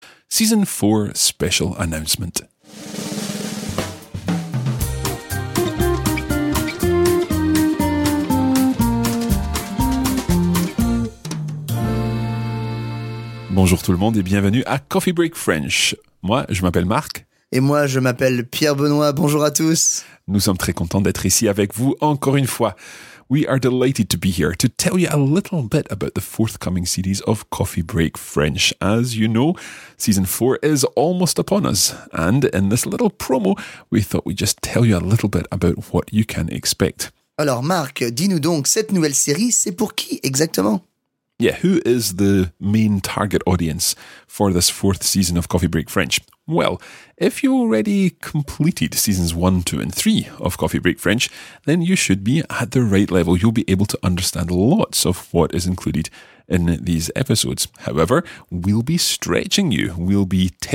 • تلفظ استاندارد و روان: تمامی اپیزودها با لهجه استاندارد فرانسوی ضبط شده‌اند که به زبان‌آموزان کمک می‌کند تلفظ خود را به سطحی حرفه‌ای‌تر برسانند.
لهجه: فرانسوی استاندارد
سرعت پخش: متوسط